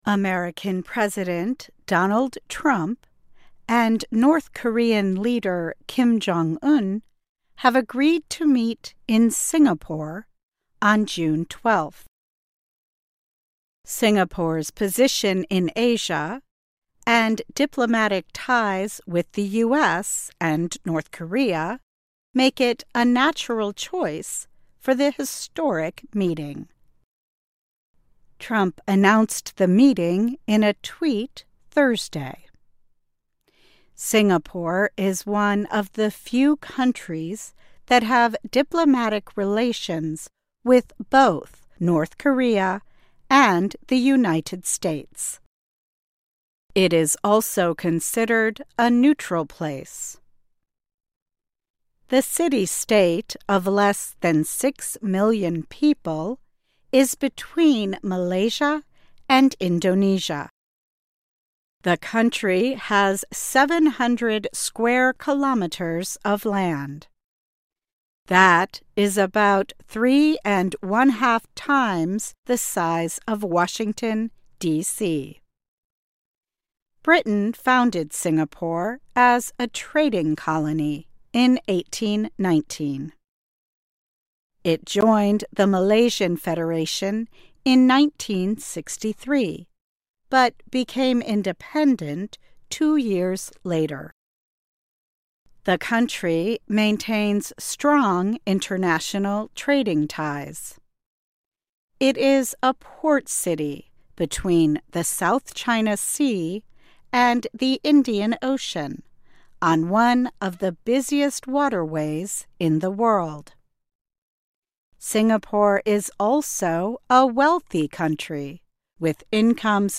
慢速英语:新加坡成为美朝峰会的最佳举办地